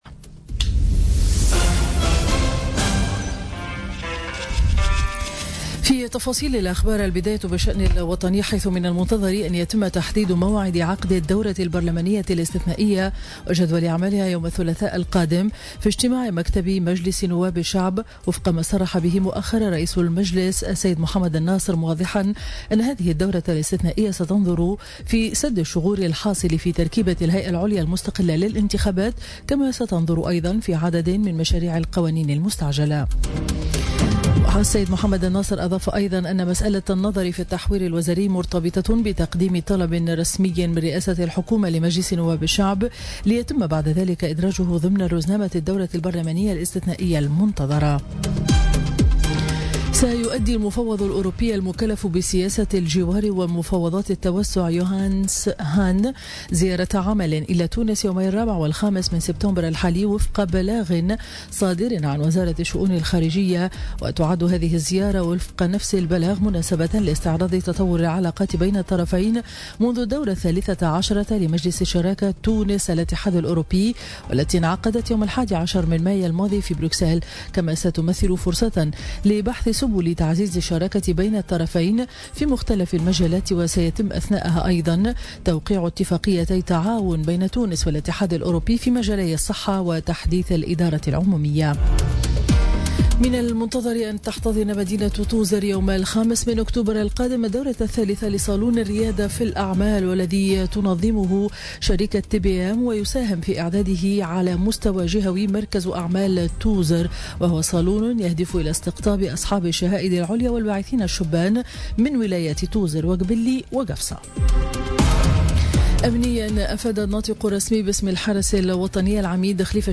نشرة أخبار السابعة صباحا ليوم الأحد 3 سبتمبر 2017